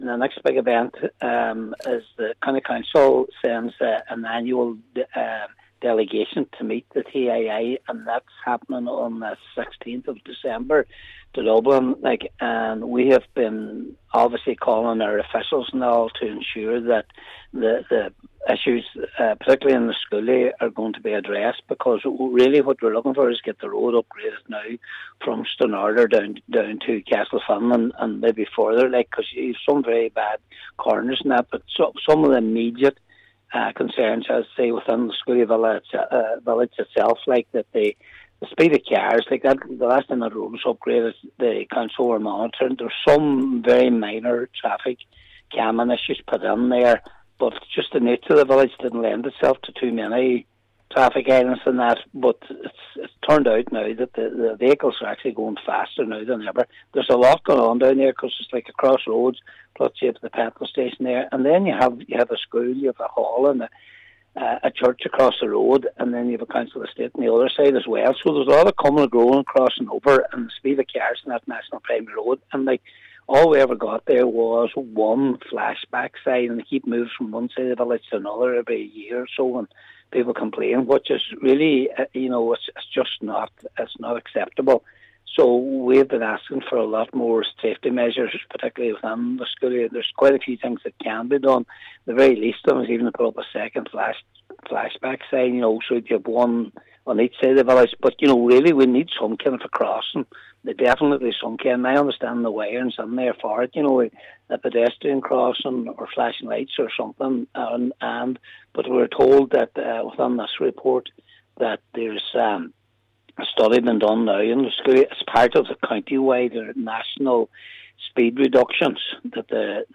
Cllr Patrick McGowan says greater traffic-calming measures must be introduced beyond the singular flashing sign currently in place: